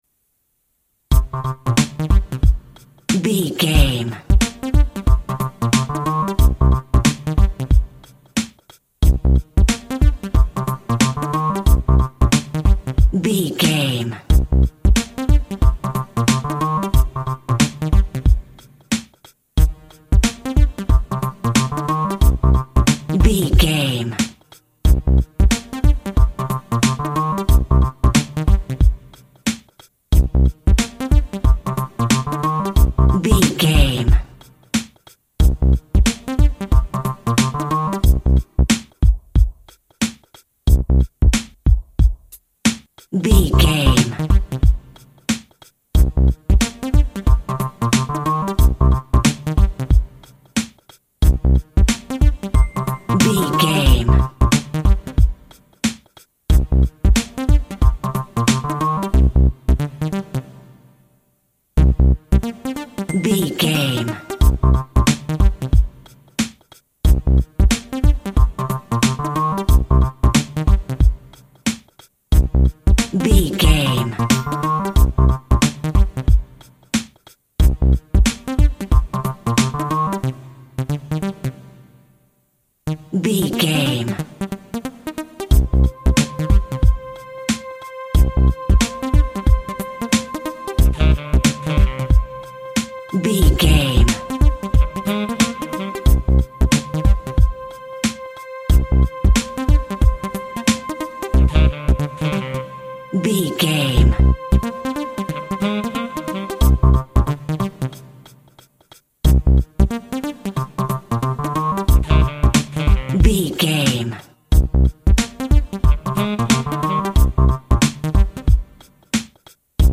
Hip Hop Looping.
Aeolian/Minor
synth lead
synth bass
hip hop synths